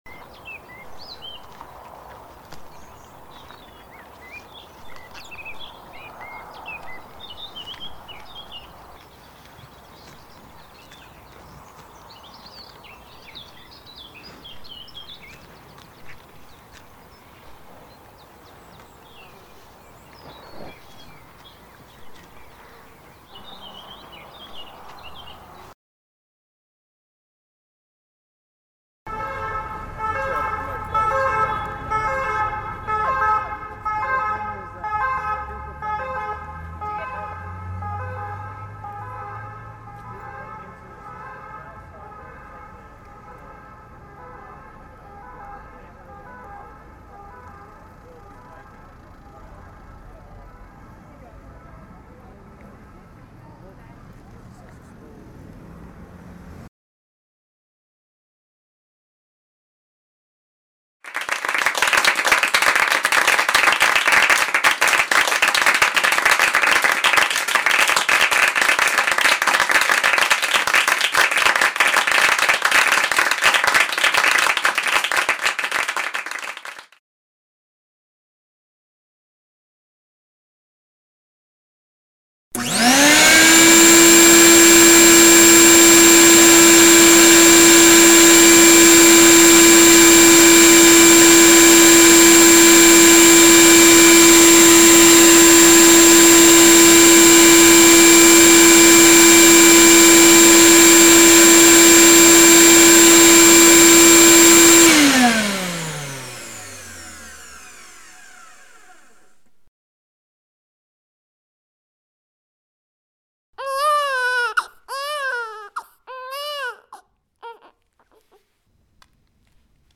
sons_atelier_5_sens.mp3